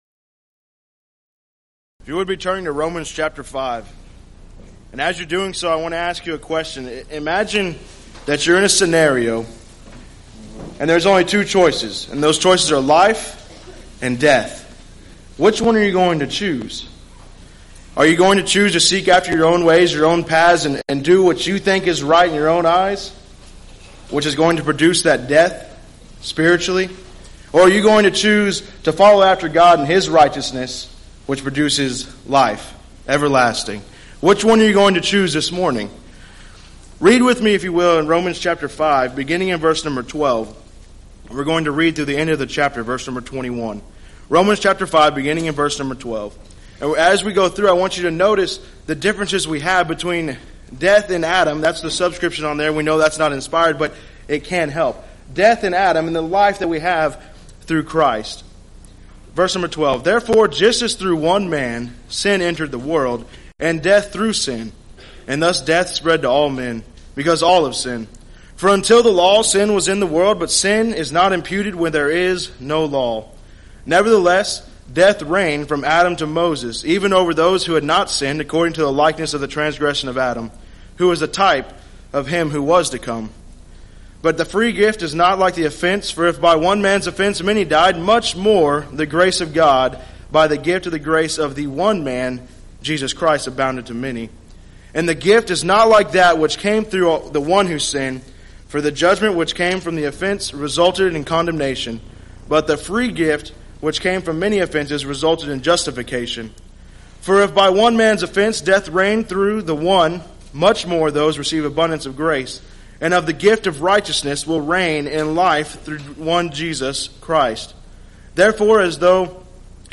Event: 2nd Annual Arise Workshop